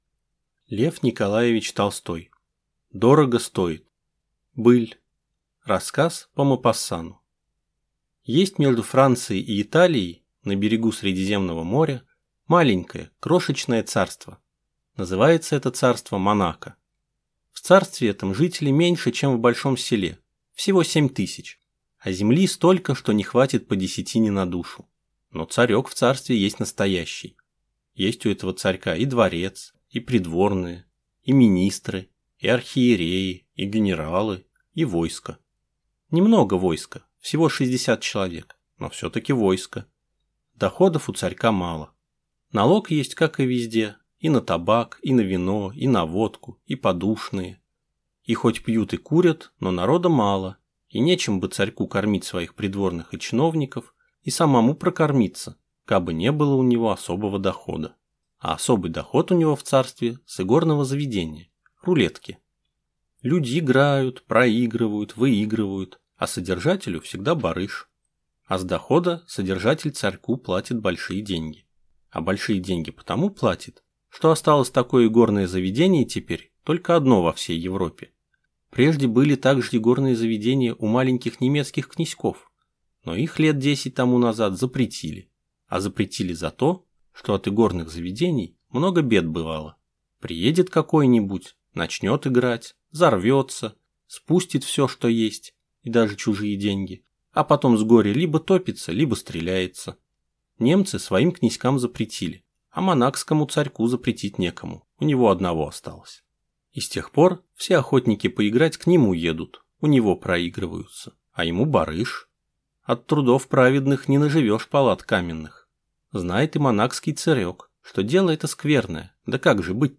Аудиокнига Дорого стоит | Библиотека аудиокниг